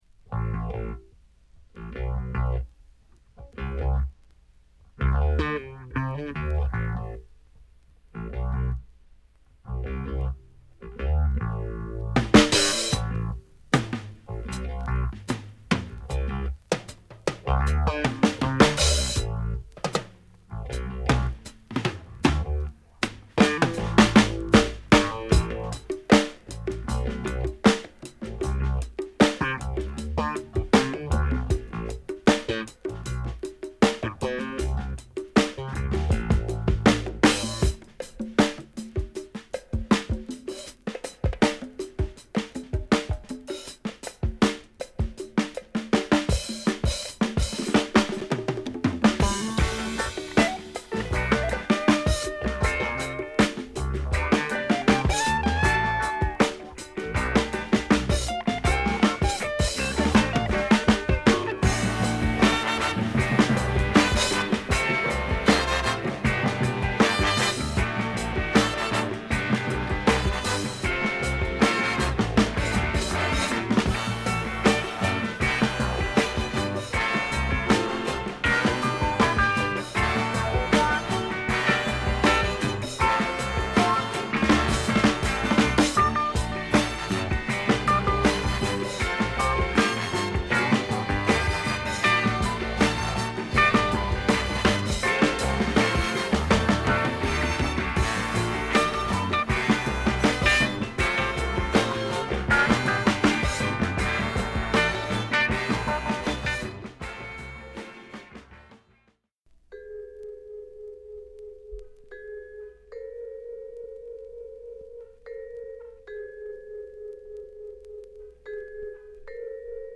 本作でもそのドープなサウンドは健在で、ヴァイオリンやホーン隊を配したジャズファンクを披露。
期待を裏切らないドープジャズファンク！